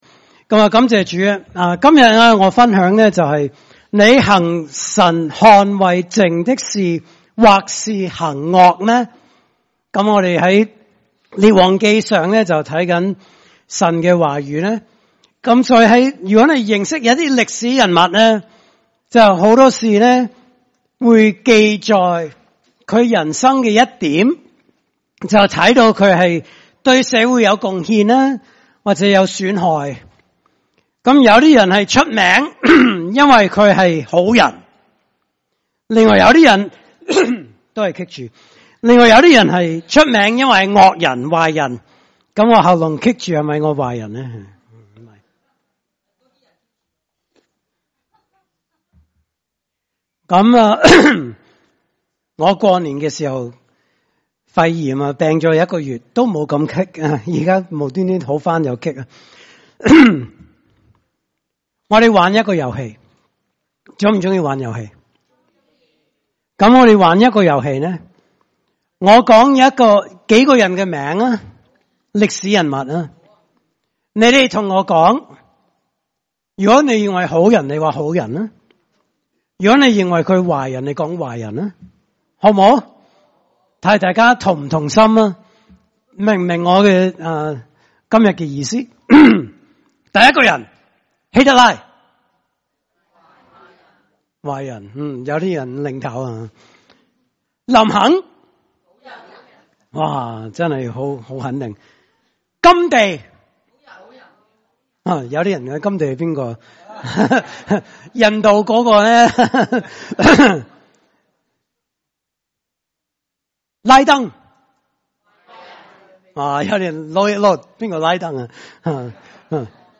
特別講員證道系列
來自講道系列 "解經式講道"